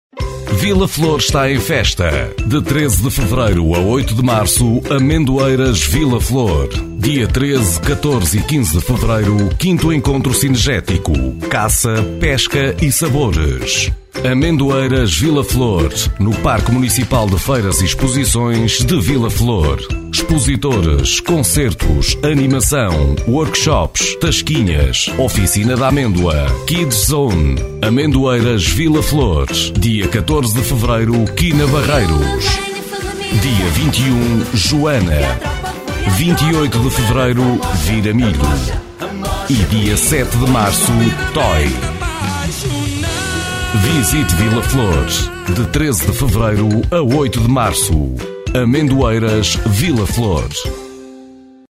spot promocional